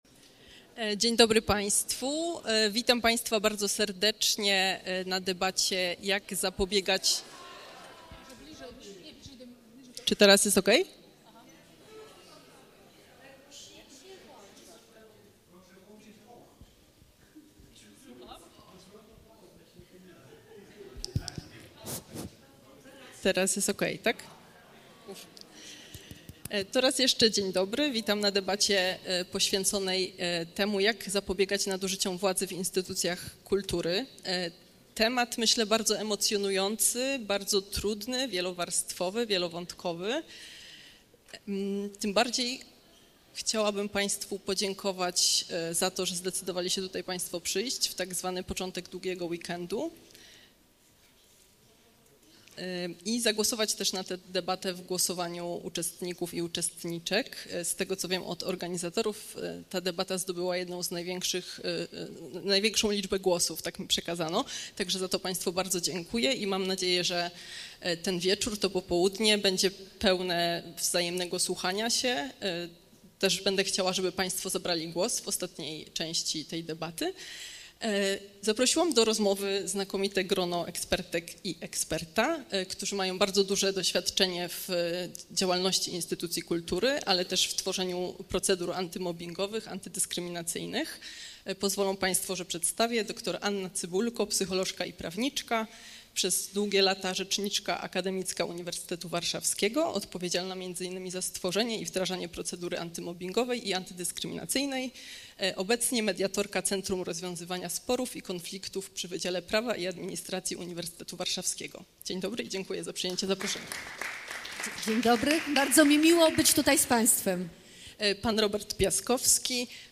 Debata odbyła się 8 listopada 2024 roku podczas współKongresu Kultury.